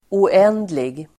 Uttal: [o'en:dlig]